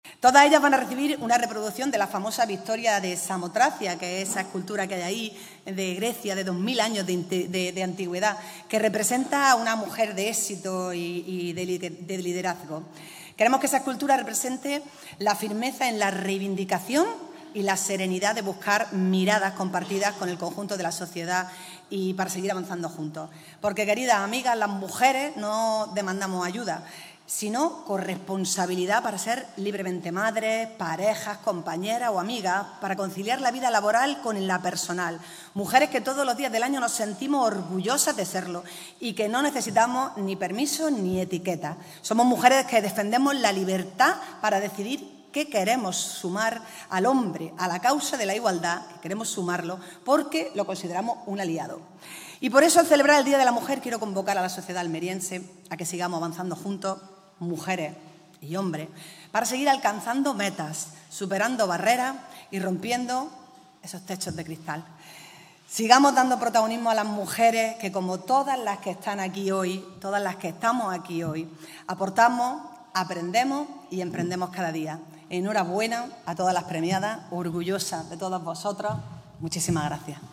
La alcaldesa, María del Mar Vázquez, afirma que “en el Ayuntamiento ponemos la igualdad real en el centro de cada decisión”
Ha sido esta tarde, en el Salón Noble de las Casas Consistoriales, presidido por la alcaldesa de Almería, María del Mar Vázquez, y dentro de la amplia programación organizada por el Área de Familia, Inclusión e Igualdad, que dirige Paola Laynez, con motivo del Día Internacional de la Mujer.
ALCALDESA-PREMIOS-8M.mp3